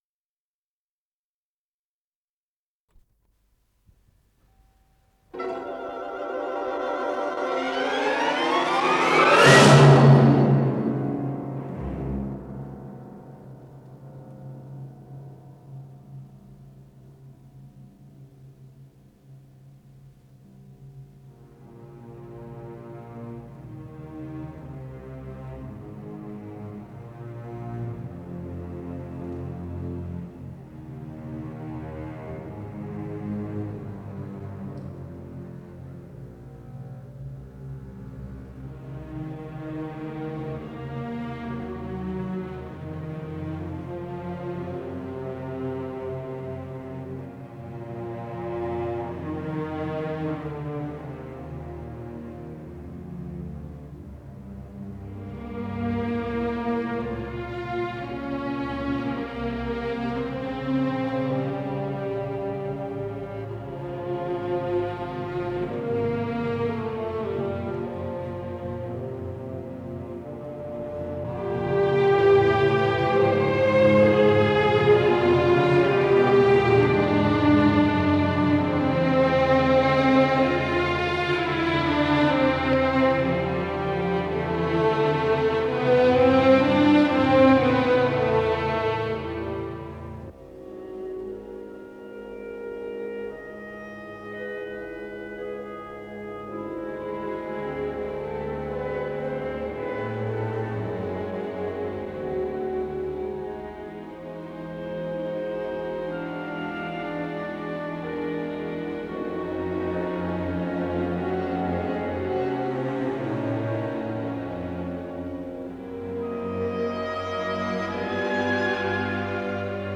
Сюита для симфонического оркестра